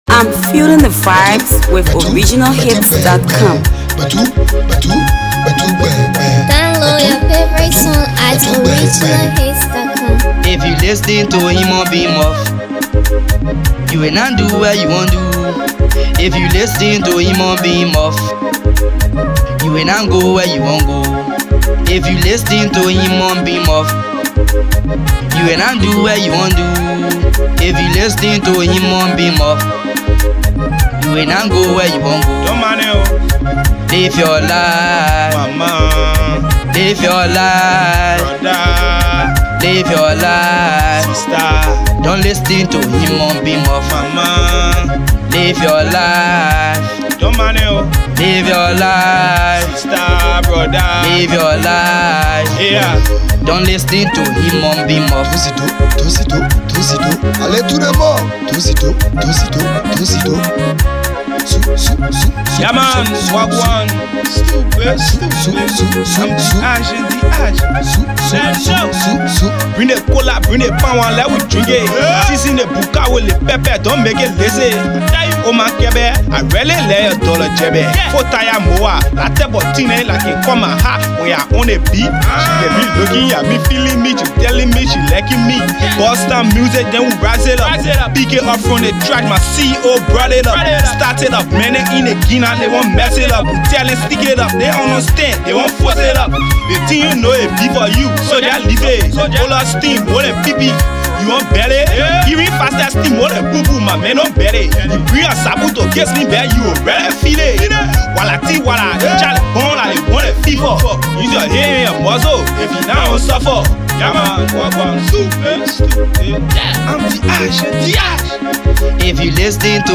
Afro Pop Music